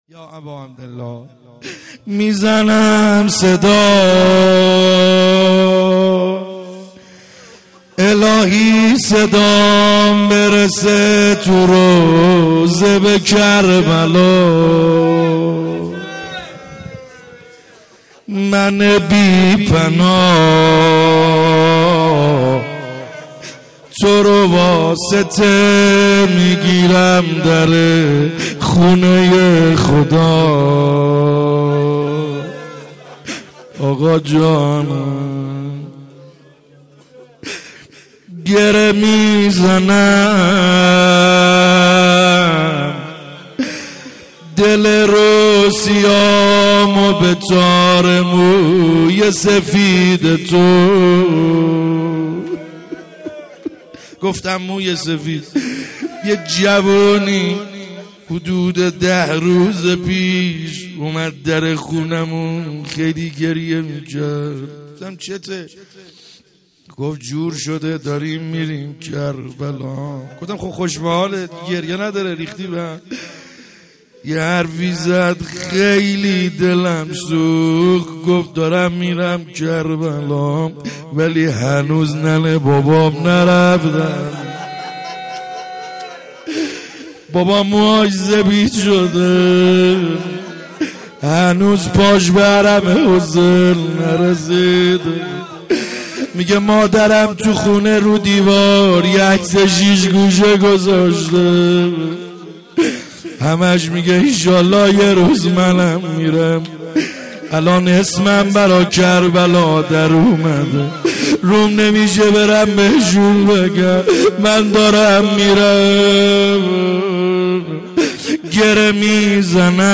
خیمه گاه - بیرق معظم محبین حضرت صاحب الزمان(عج) - روضه ا امام باقر علیه السلام